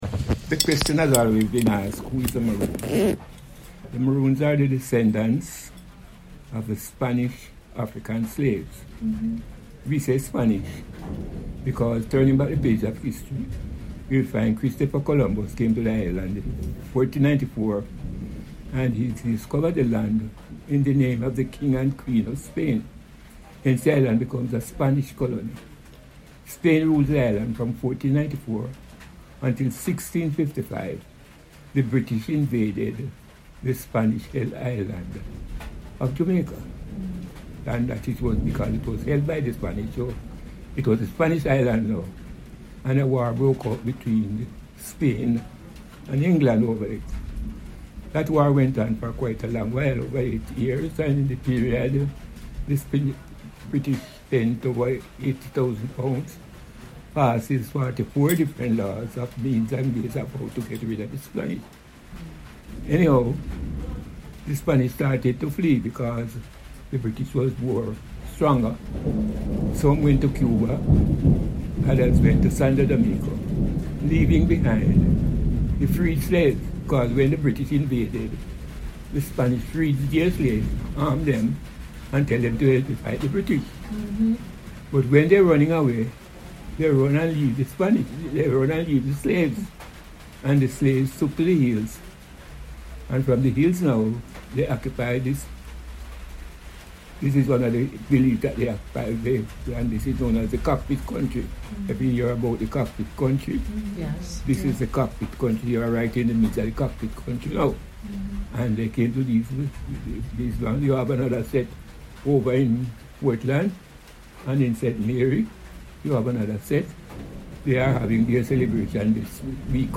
This is a very small part of a wonderful exchange.